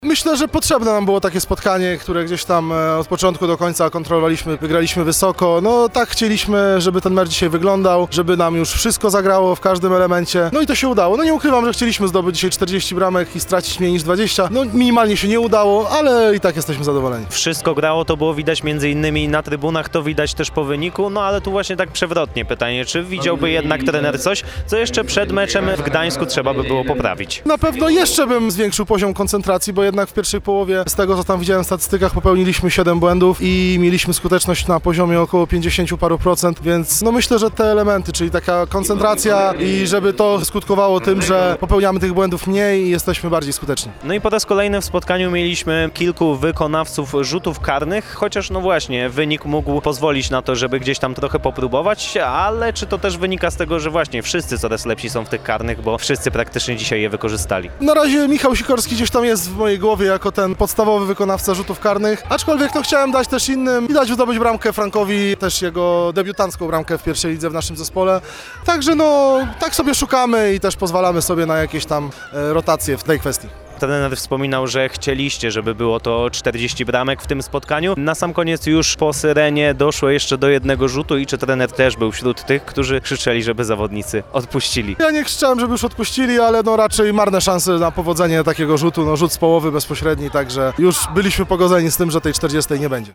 mówił tuż po zakończeniu spotkania